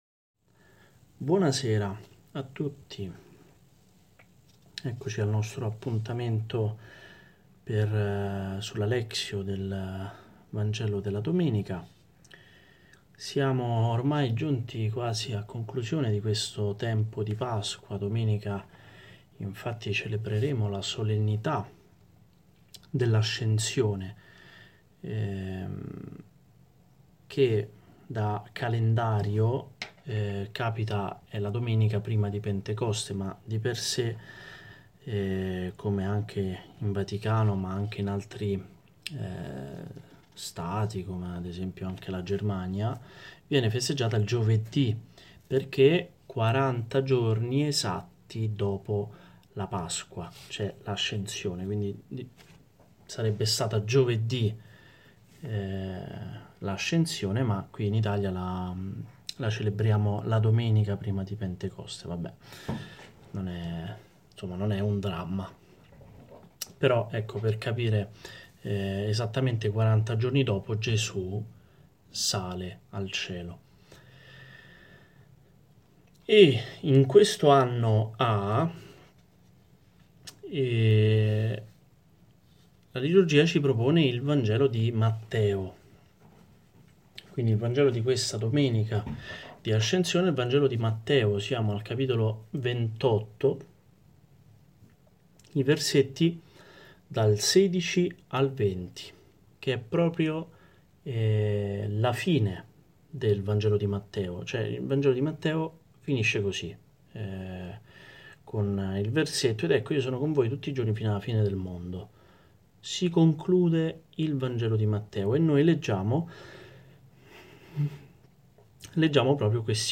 Tipo: Audio Catechesi